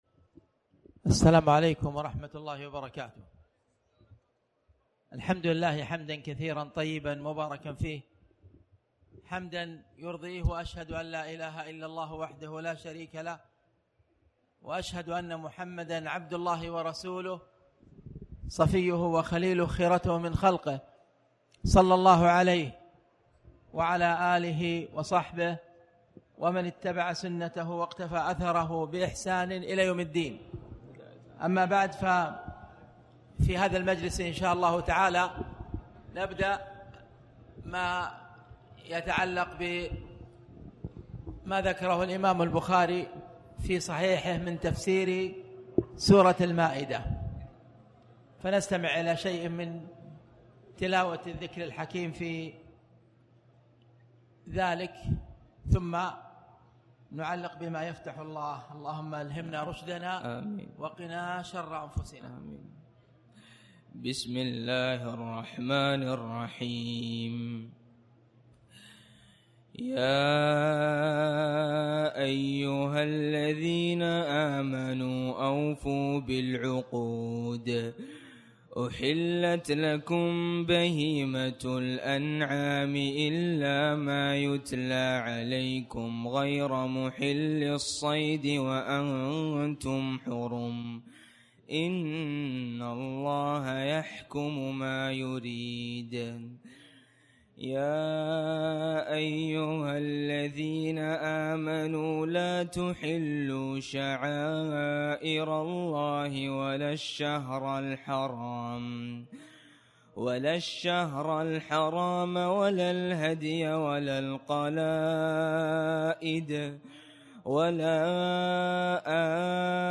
تاريخ النشر ١٢ رمضان ١٤٣٩ هـ المكان: المسجد الحرام الشيخ: فضيلة الشيخ أ.د. خالد بن عبدالله المصلح فضيلة الشيخ أ.د. خالد بن عبدالله المصلح (اليوم أكملت لكم دينكم)